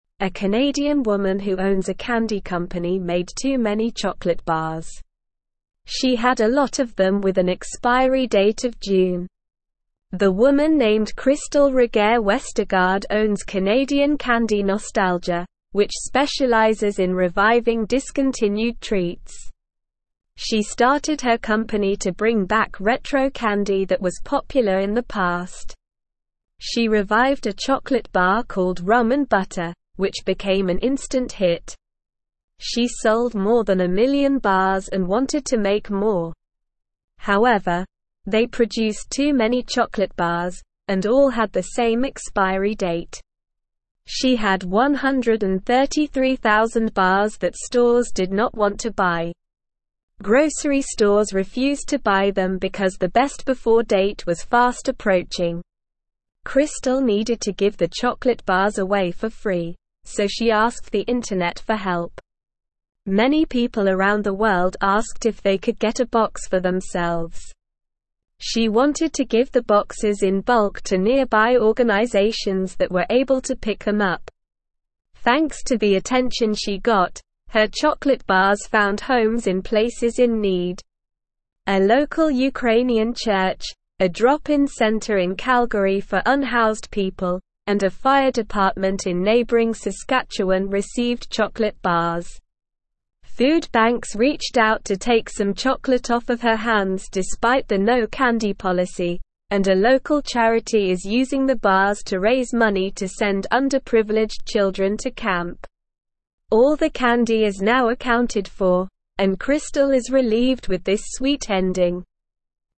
Slow
English-Newsroom-Beginner-SLOW-Reading-Candy-Company-Gives-Excess-Chocolate-to-Charity.mp3